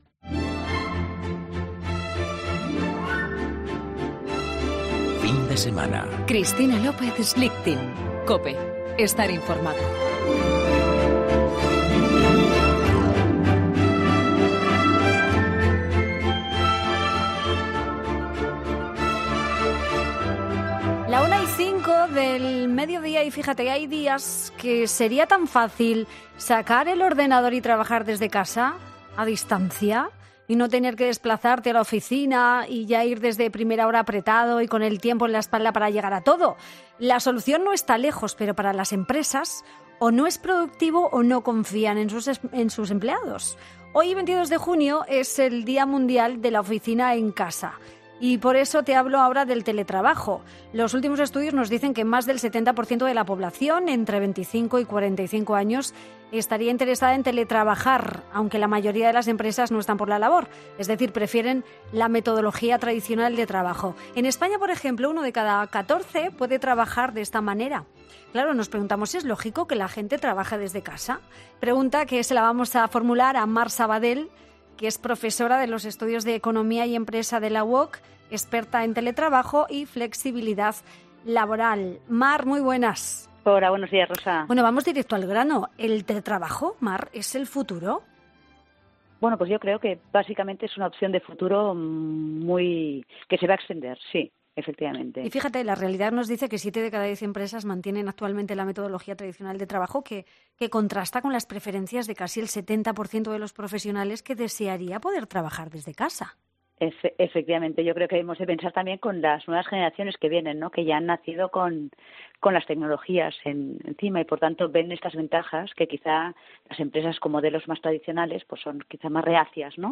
Ha dado algunas claves en Fin de Semana de Cristina L. Schlichting sobre los peligros que puede tener adoptar esta emergente forma de trabajo.